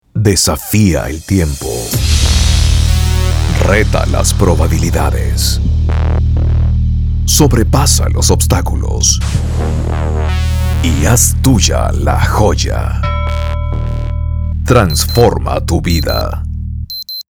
Professional Spanish Latin American Neutral • Conversational • Friendly • Natural • Commercials • Documentaries • Corporate Narrations.
Sprechprobe: eLearning (Muttersprache):
Full time Professional Voice Over Artist. As a Native Spanish Male Voice Talent, has a wide range of vocal skills